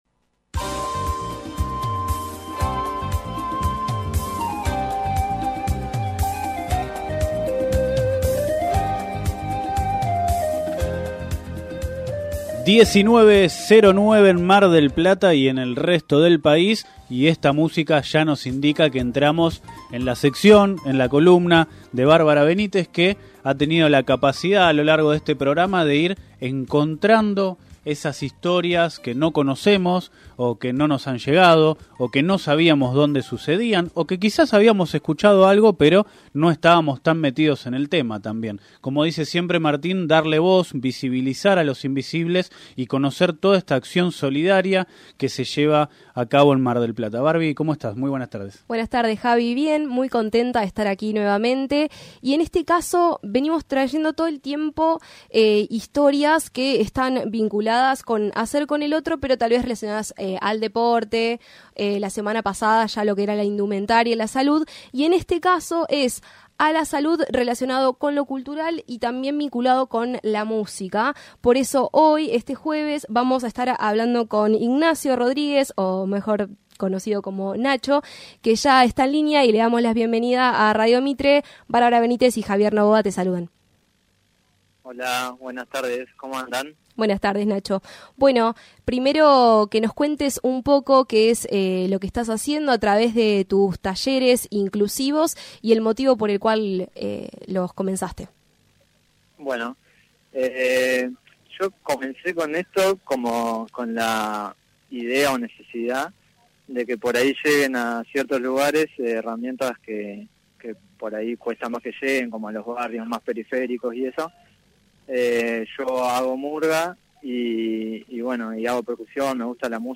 emitido por Radio Mitre Mar del Plata (FM 103.7)